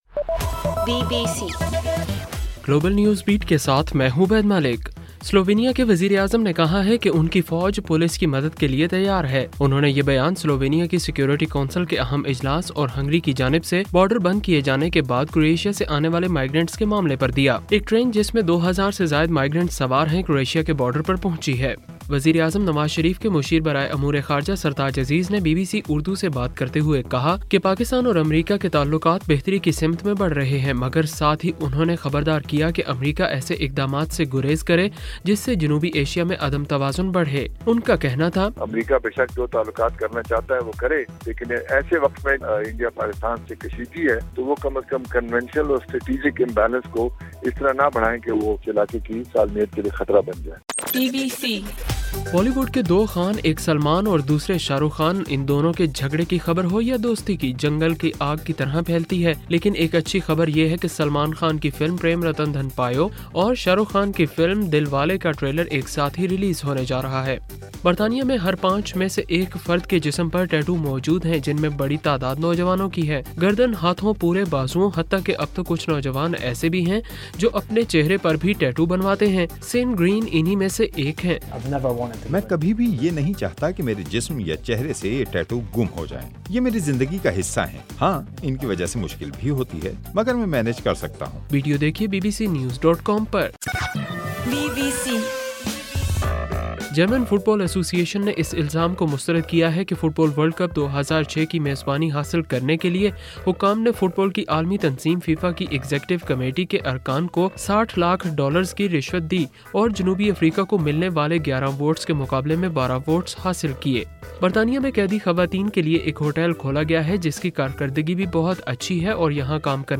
اکتوبر 18:صبح 1 بجے کا گلوبل نیوز بیٹ بُلیٹن